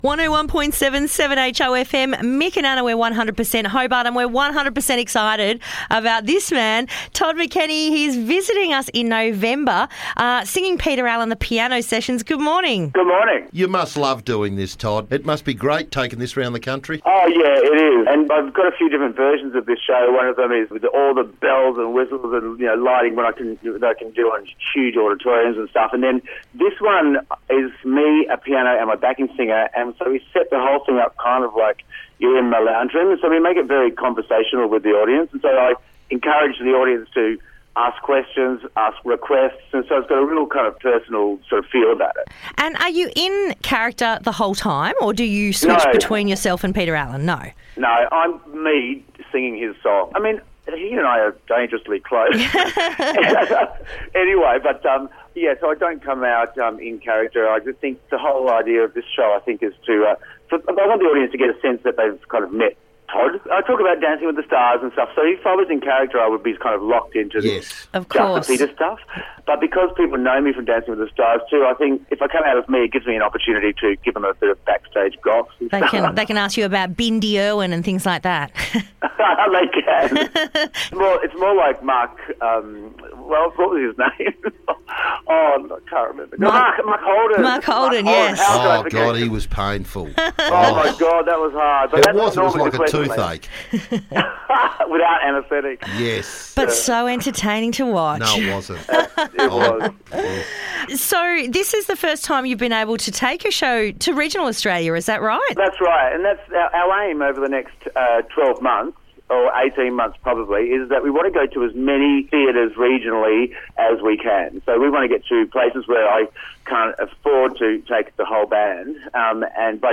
Todd McKenney brings his "The Piano Sessions" show to Wrest Point in November and we had a chat to the song and dance man.